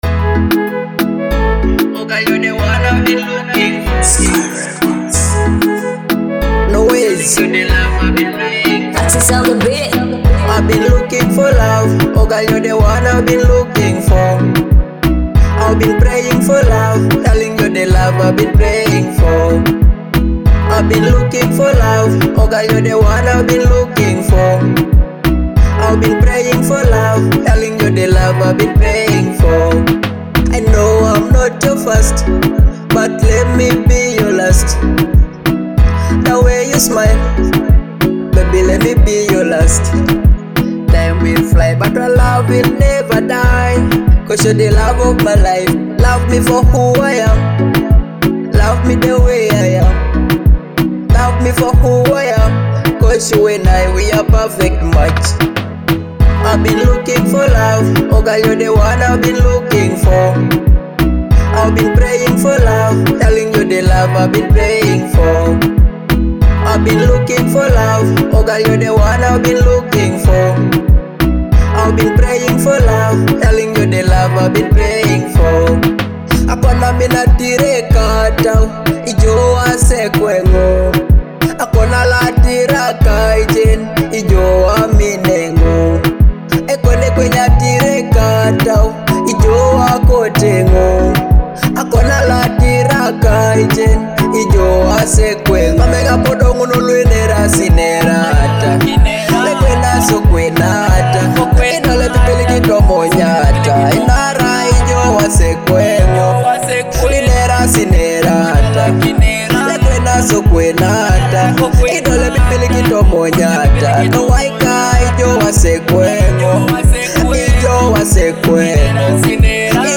smooth Afro-fusion vibe